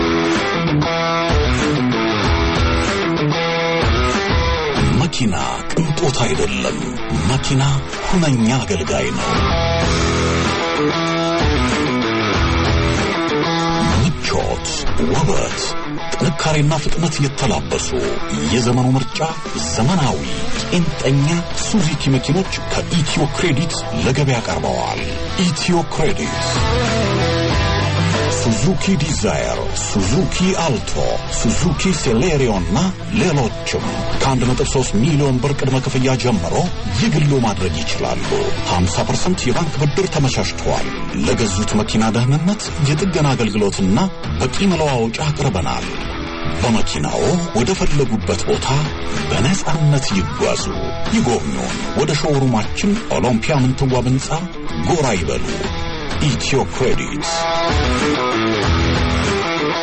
Advert: Credit to buy Suziki Cars